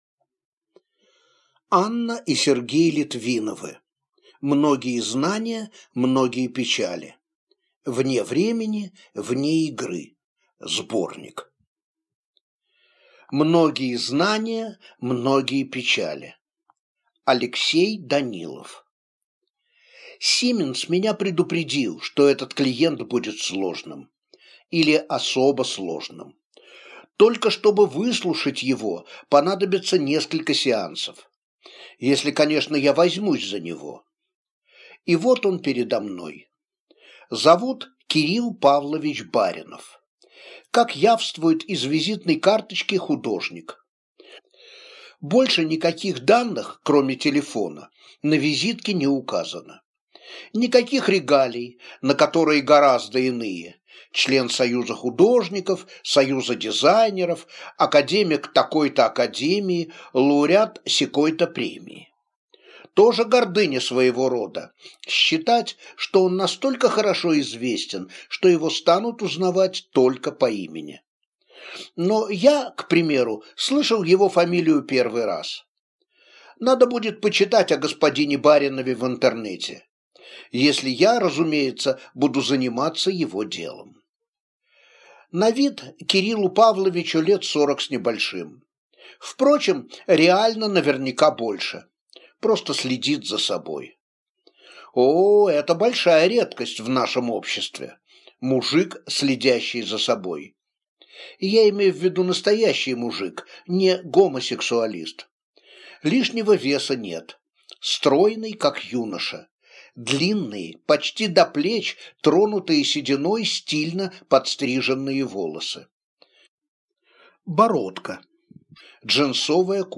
Аудиокнига Многие знания – многие печали. Вне времени, вне игры (сборник) | Библиотека аудиокниг